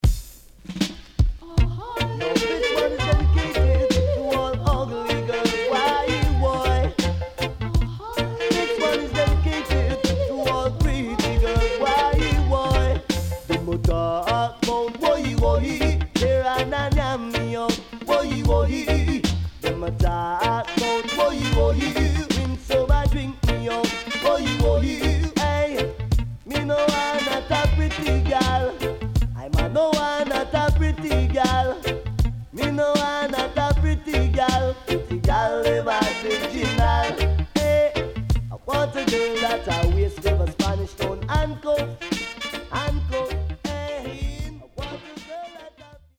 HOME > LP [DANCEHALL]
SIDE A:少しノイズ入りますが良好です。